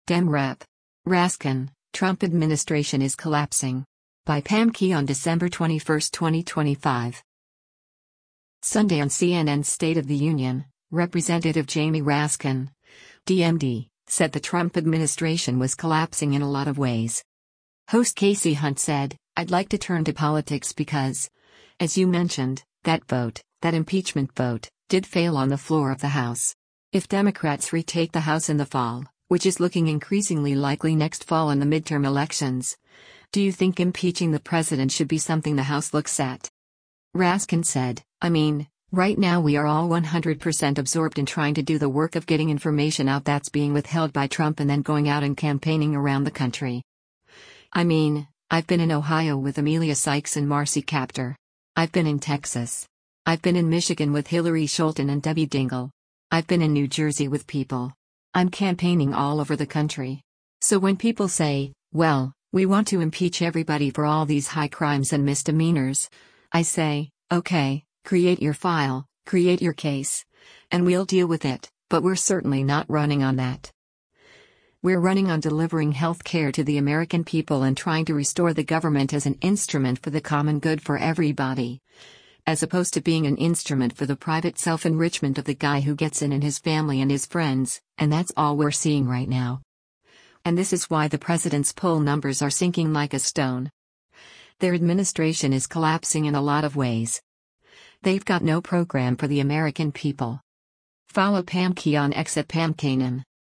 Sunday on CNN’s “State of the Union,” Rep. Jamie Raskin (D-MD) said the Trump administration was “collapsing in a lot of ways.”